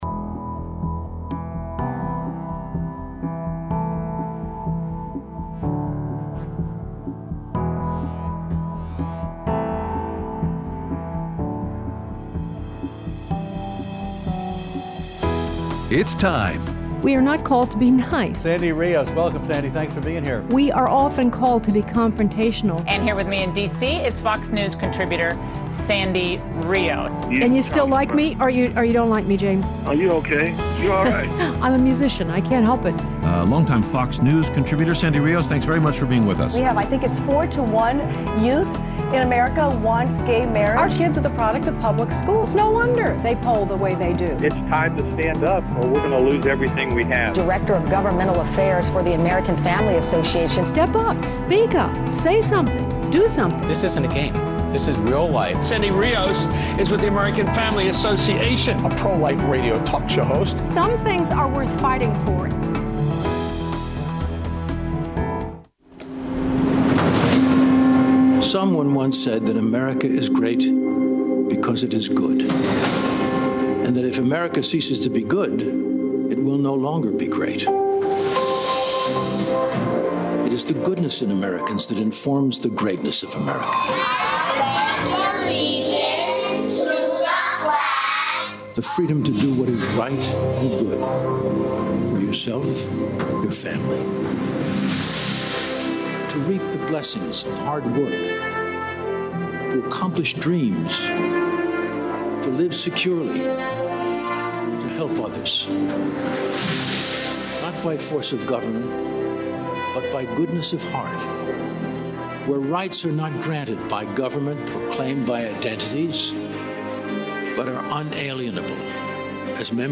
Recap of 4th Day of Republican National Convention and Your Phone Calls